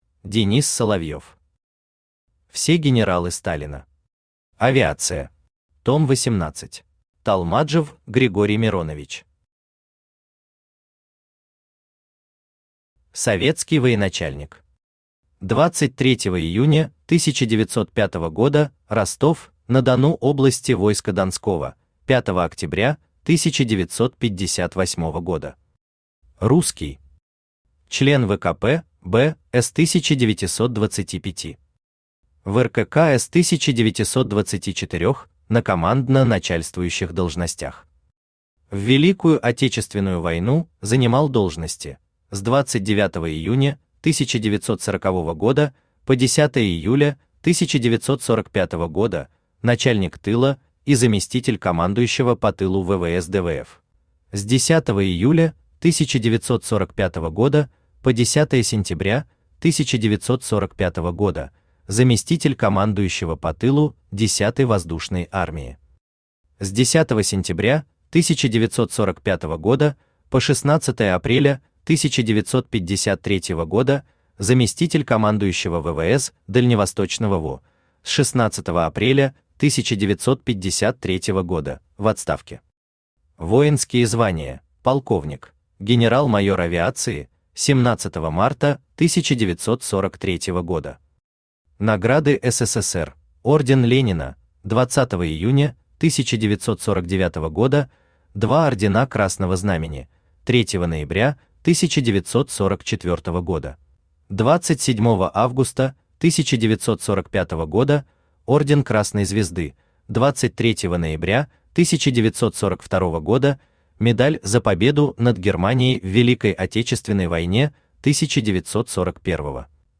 Аудиокнига Все генералы Сталина. Авиация. Том 18 | Библиотека аудиокниг
Том 18 Автор Денис Соловьев Читает аудиокнигу Авточтец ЛитРес.